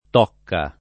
[ t 0 kka ]